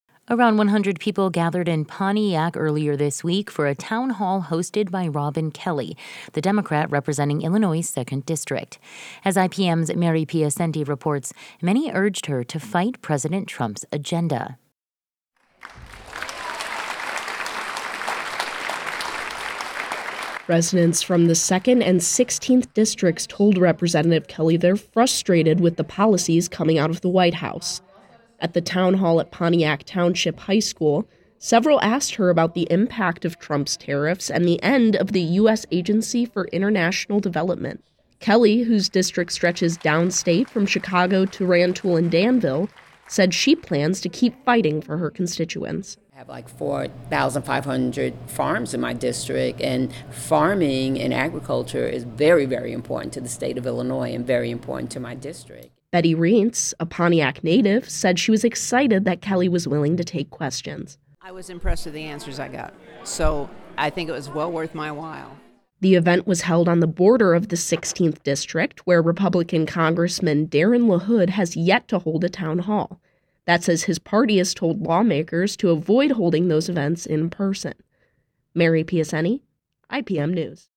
Rep. Robin Kelly takes questions, addresses federal policies at Pontiac town hall
Robin-Kelly-Town-Hall.mp3